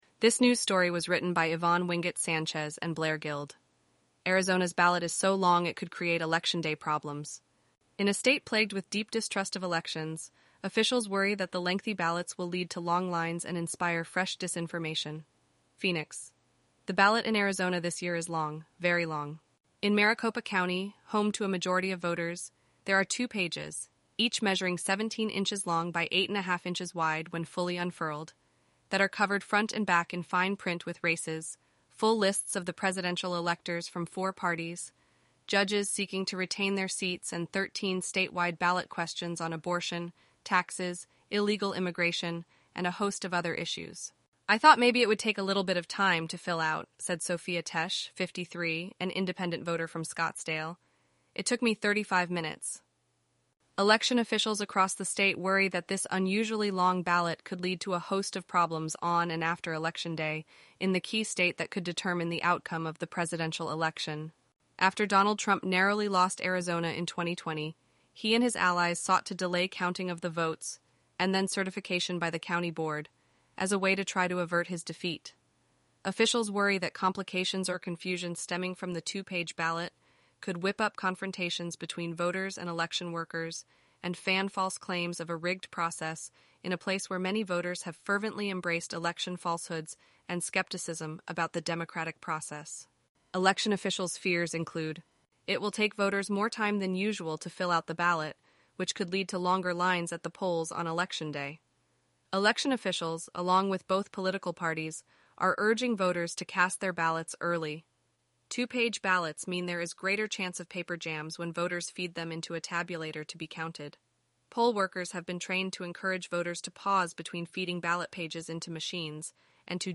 eleven-labs_en-US_Rachel_standard_audio.mp3